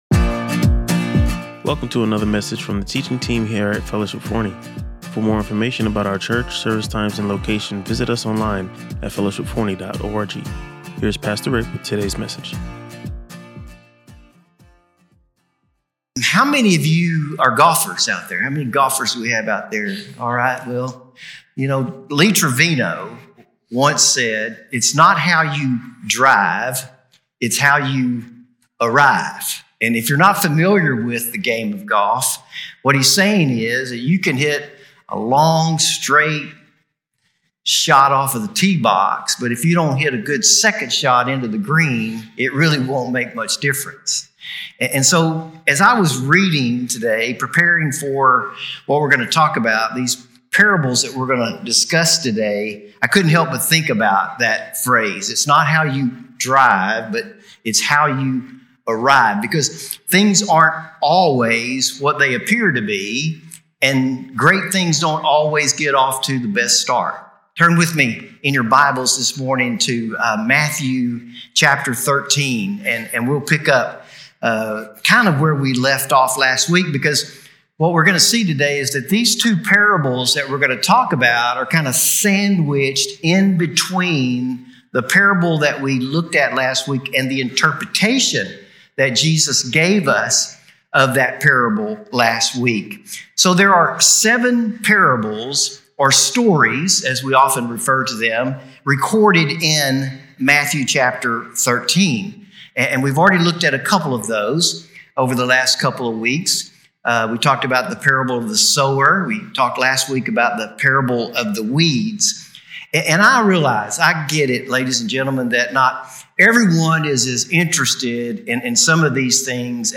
From the Smallest to the Greatest | Matthew 13:31-35 | Fellowship Forney